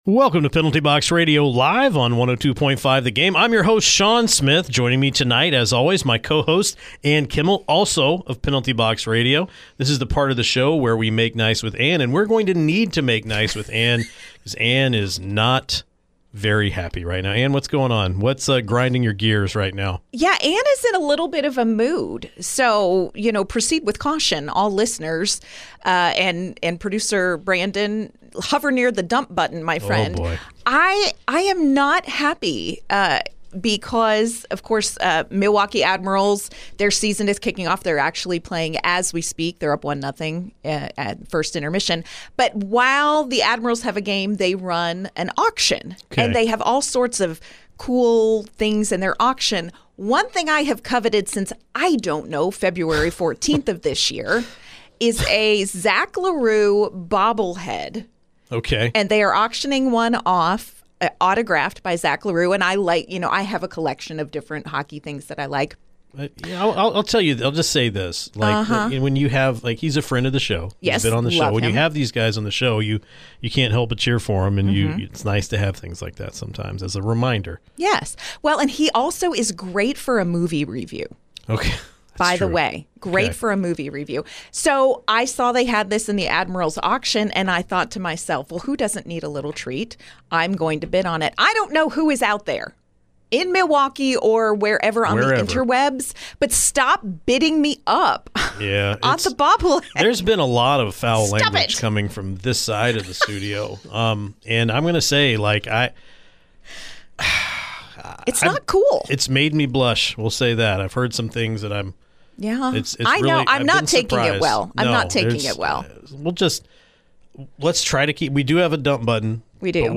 Subscribe for Nashville's Best Sports talk on the Titans, Vols, Preds, Vandy, Sportsbetting and more!